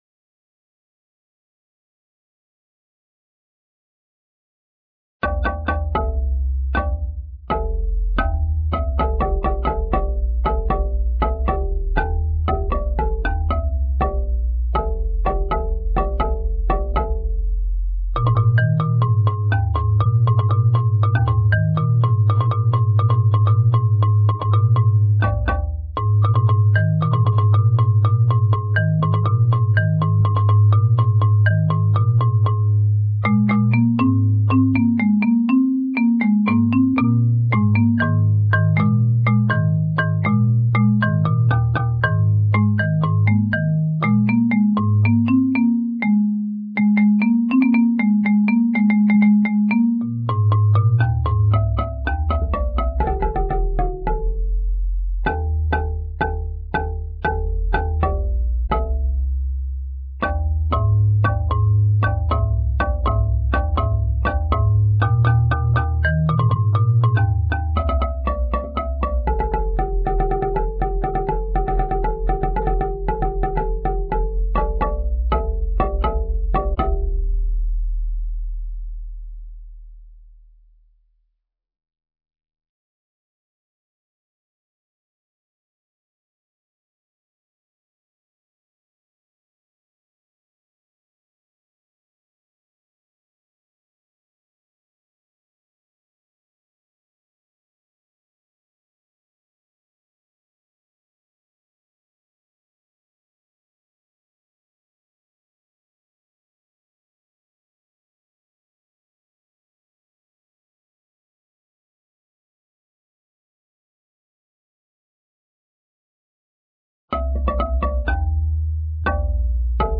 Royalty free Asian inspired marimba melody.